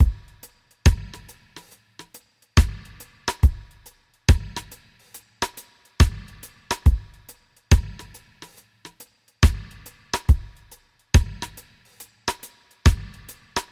dm 70鼓加工2
描述：DuB HiM丛林onedrop rasta Rasta雷鬼雷鬼根源根
Tag: onedrop 丛林 配音 雷鬼 拉斯特 拉斯塔 雷鬼